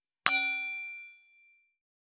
311シャンパングラス,ウィスキーグラス,ヴィンテージ,ステンレス,金物グラス,
効果音厨房/台所/レストラン/kitchen食器
効果音